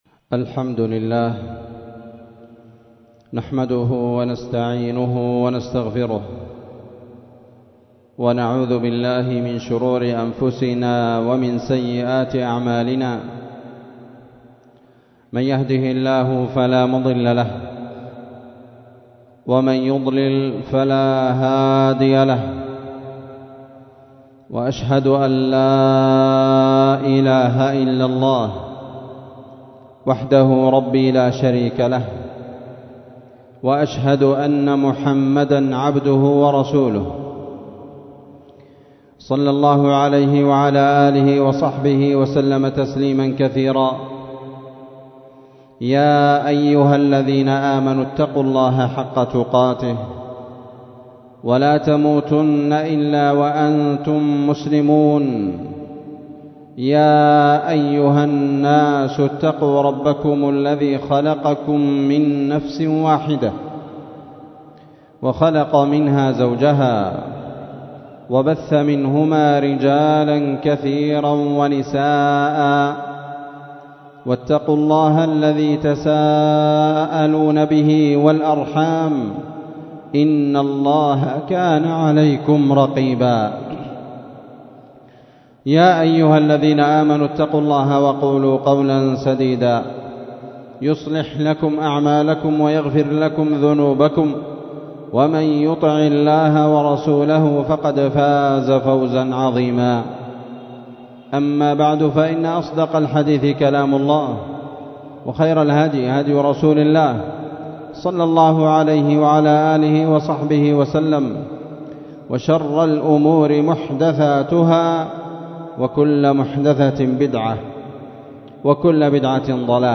خطبة بعنوان عبر من أحداث غزة